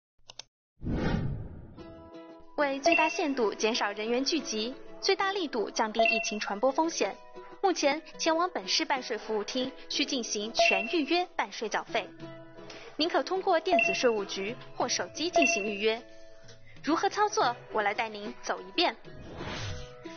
为减少人员聚集，降低疫情传播风险，目前，纳税人缴费人前往本市办税服务厅需进行“全预约”办税缴费。 请您提前通过上海市电子税务局、上海税务微服务等渠道进行预约，下面，税务主播将带您手把手体验“全预约”办税缴费流程，一起来看一看吧~